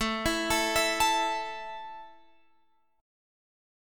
A5 chord {17 19 19 x 17 17} chord